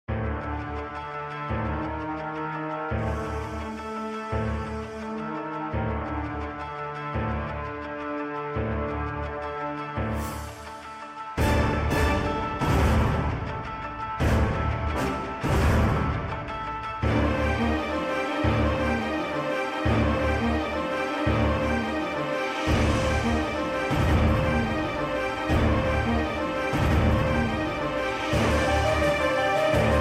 Boss Theme
Ripped from the official soundtrack